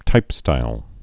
(tīpstīl)